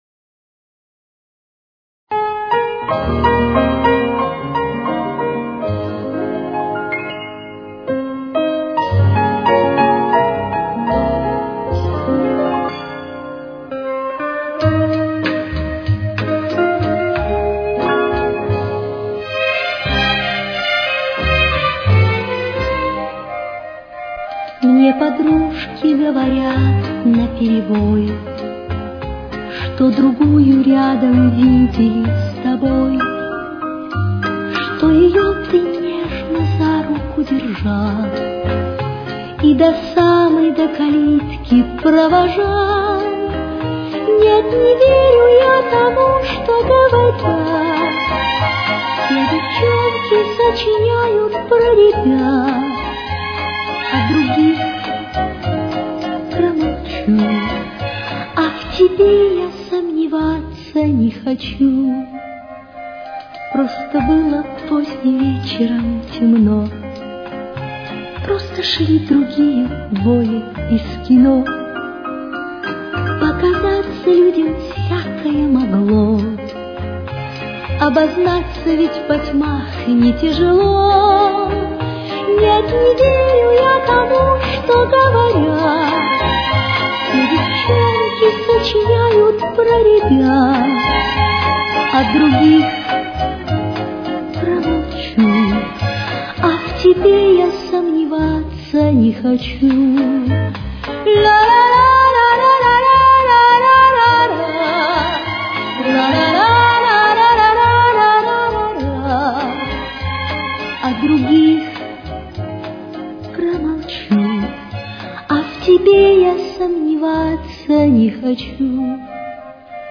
с очень низким качеством (16 – 32 кБит/с)
До минор. Темп: 102.